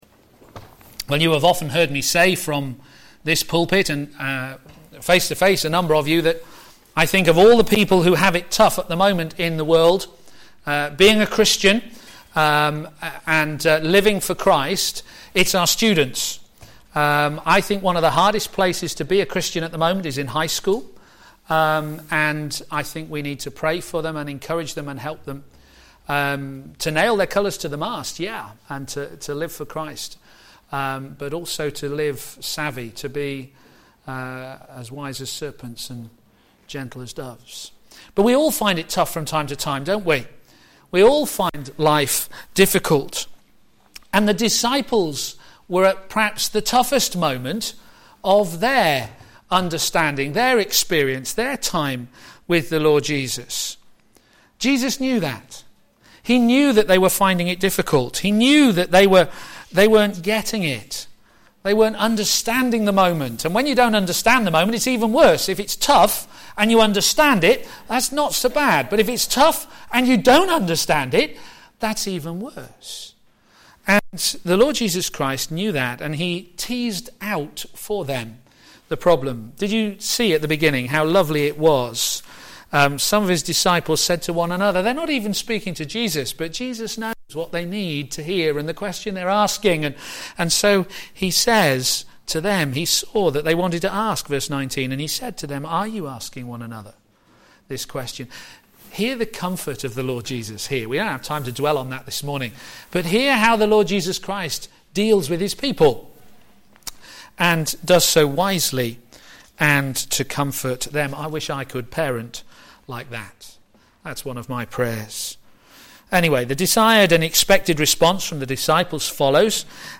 Media for a.m. Service on Sun 01st Mar 2015 10:30
Passage: John 16: 16-33 Series: John on Jesus Theme: Sermon